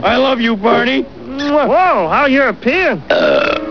Europe.wav      21k                    Homer kisses Barney goodbye.....